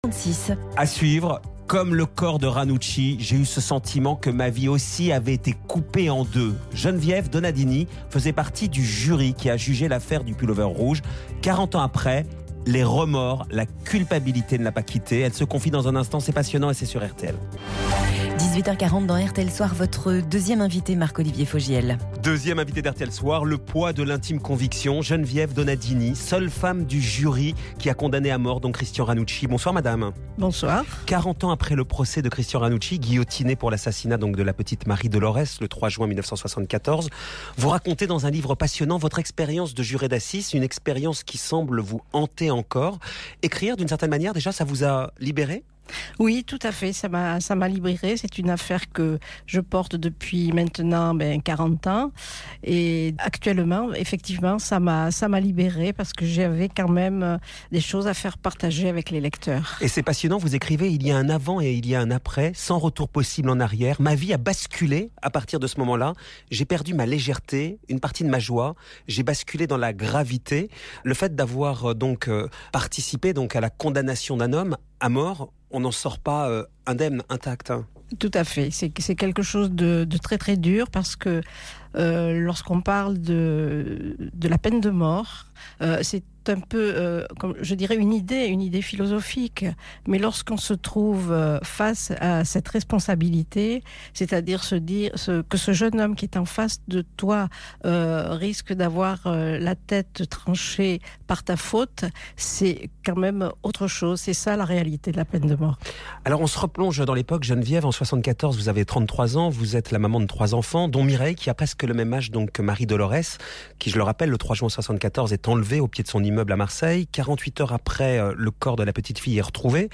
Émission Radio